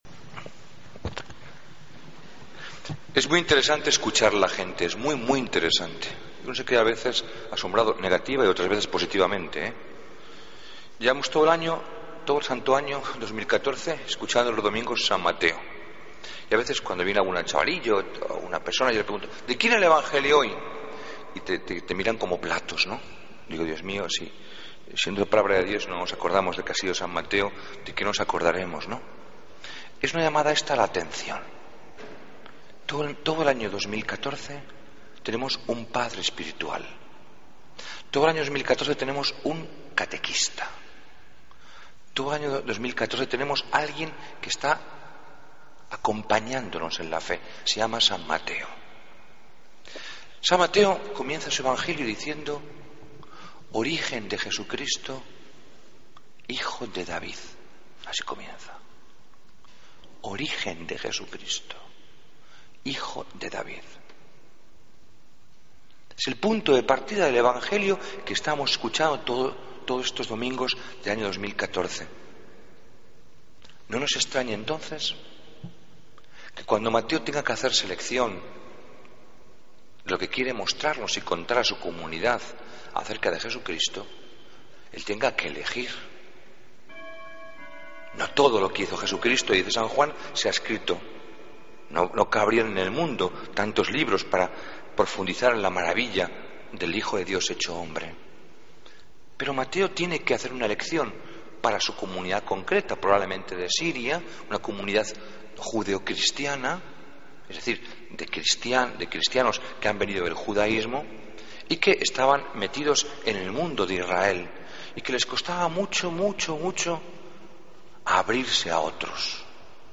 Homilía del 17 de agosto